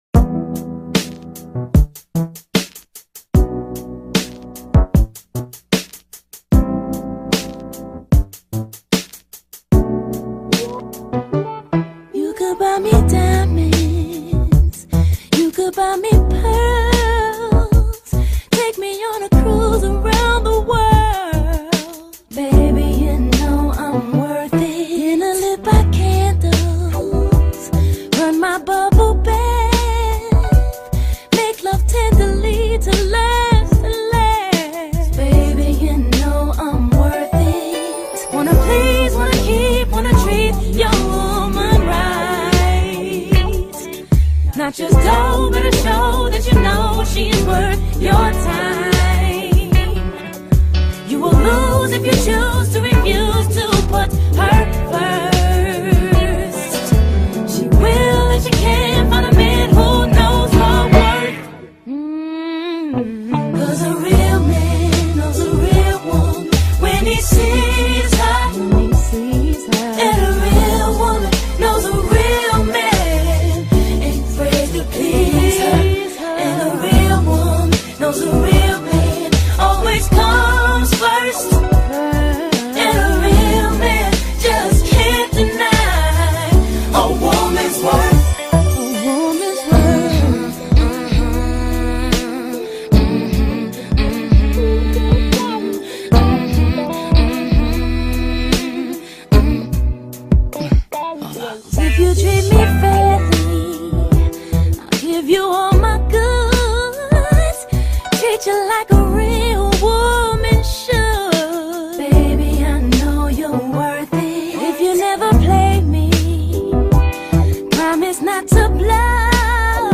ballada soulowa z 2001 roku